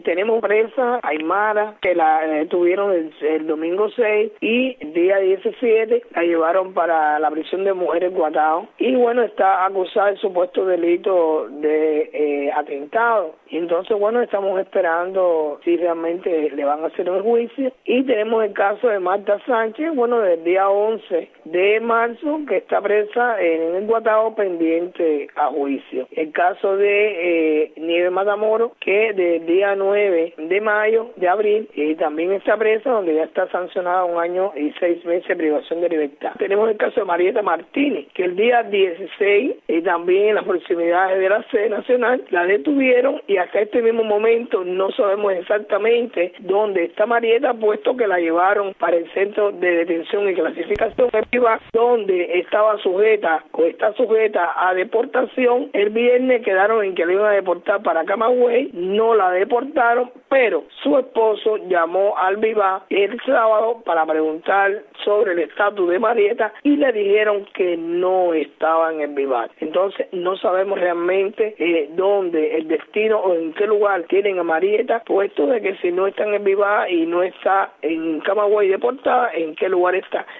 Declaraciones de Berta Soler a Radio Martí.